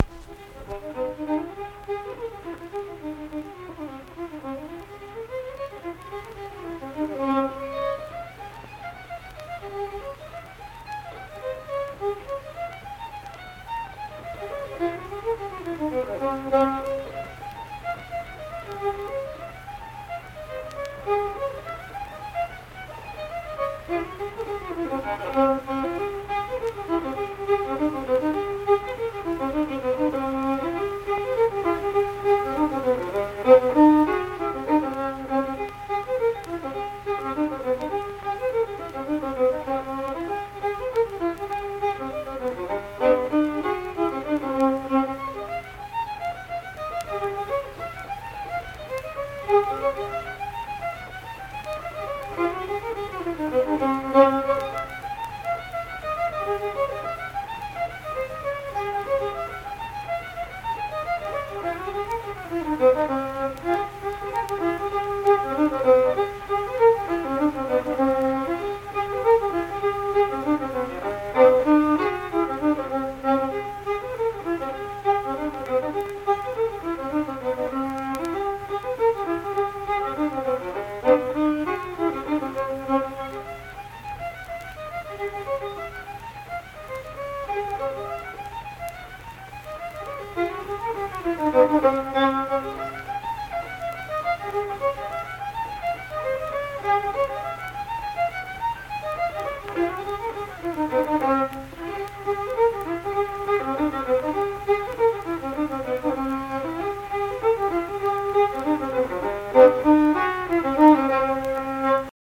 Unaccompanied fiddle music
Instrumental Music
Fiddle
Saint Marys (W. Va.), Pleasants County (W. Va.)